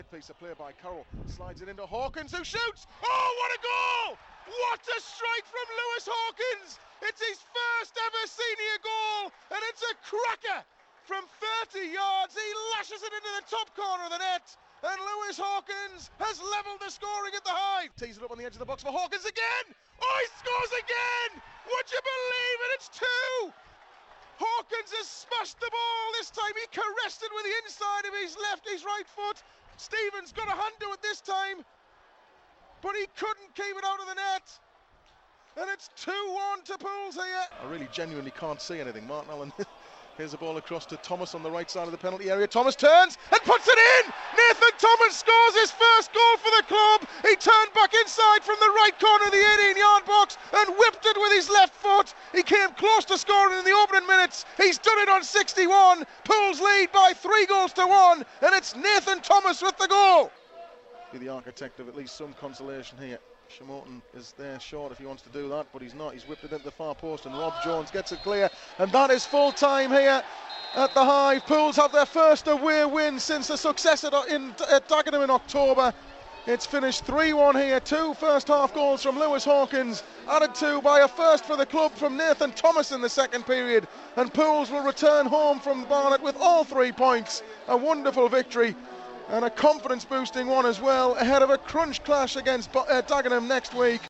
Listen back to how the key moments from Saturday's brilliant win at Barnet sounded as they happened on Pools PlayerHD's live commentary